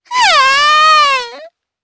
Hanus-Vox_Dead_jp.wav